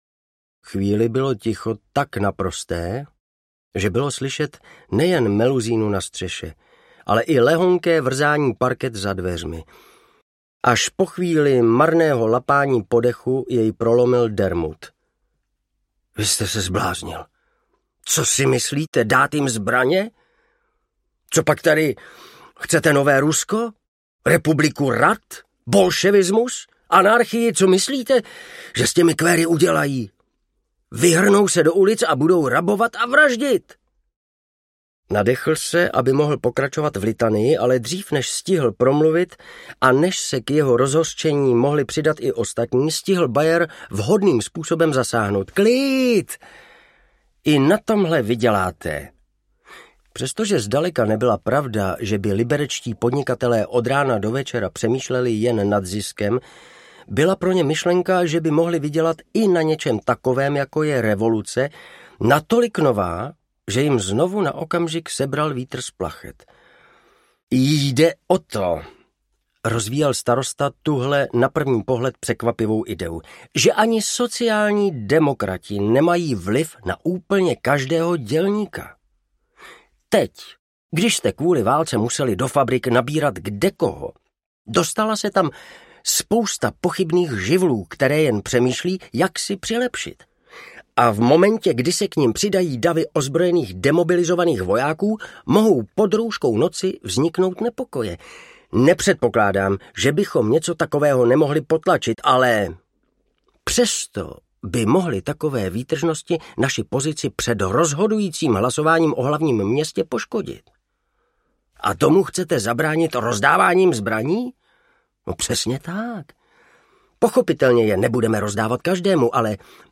Dlouhý krok do tmy audiokniha
Ukázka z knihy
Vyrobilo studio Soundguru.